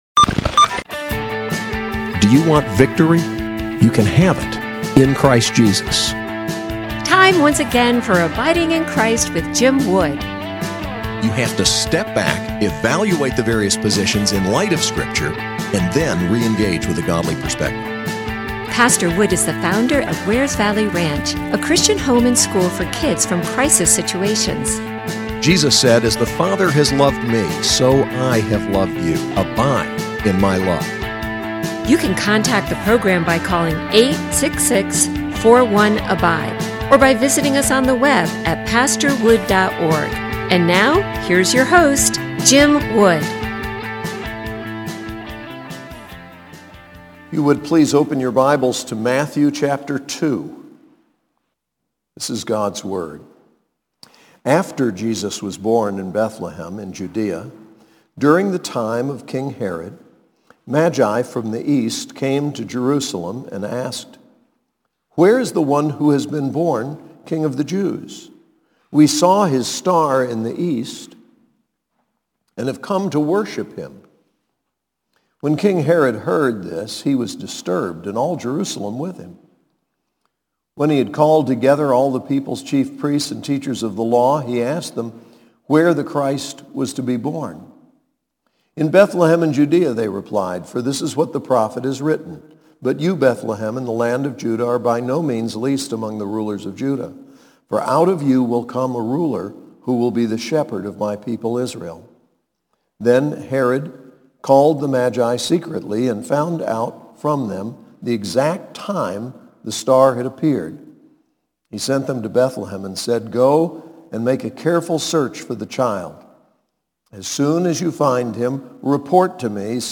SAS Chapel